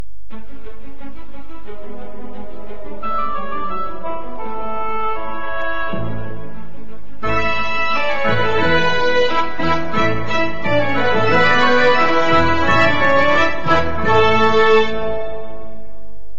Indicatiu musical de l'emissora